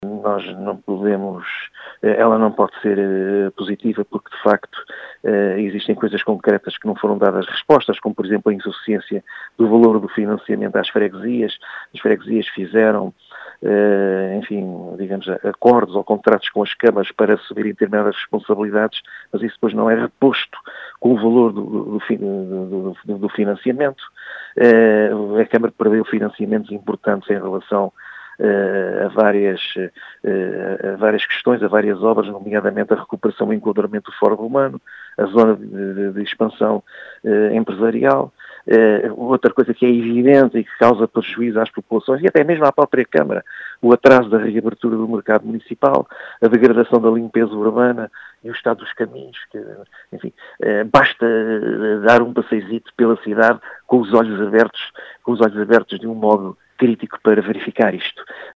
Em declarações à Rádio Vidigueira, Bernardo Loff, eleito da CDU, afirma que a actividade da Câmara de Beja, “não pode ser positiva” uma vez que  “não foram dadas respostas” a algumas questões.